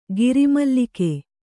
♪ giri mallike